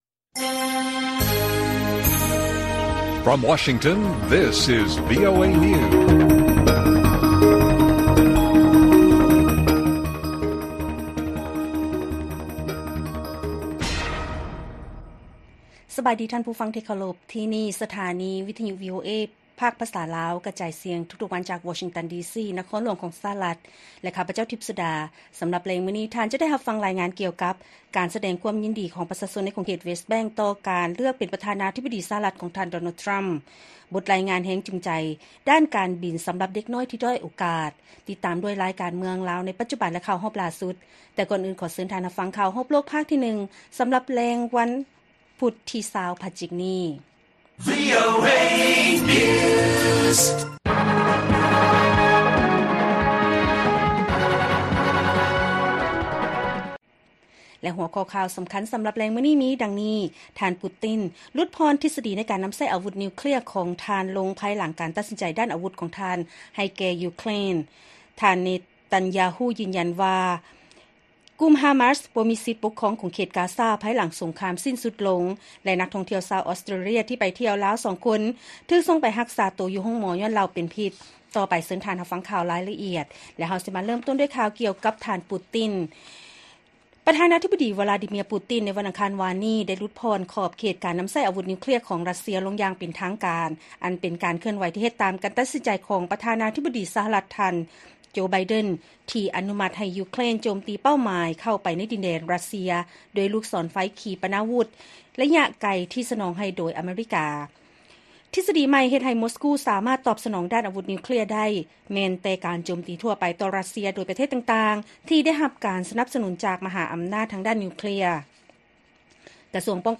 ລາຍການກະຈາຍສຽງຂອງວີໂອເອລາວ: ທ່ານ ປູຕິນ ຫຼຸດຜ່ອນທິດສະດີໃນການນຳໃຊ້ອາວຸດນິວເຄລຍລົງ ພາຍຫຼັງ ການຕັດສິນໃຈດ້ານອາວຸດຂອງ ທ່ານ ໄບເດັນ ໃຫ້ແກ່ ຢູເຄຣນ